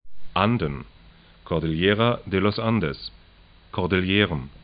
'andn
kɔrdɪl'je:rən
kɔrdɪl'je:ra de lɔs 'andɛs